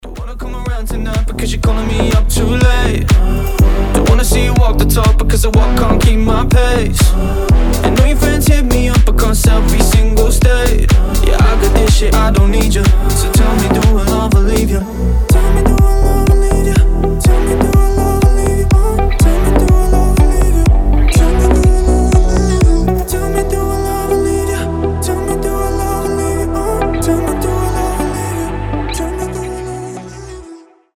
• Качество: 320, Stereo
мужской голос
Electronic
EDM
басы
house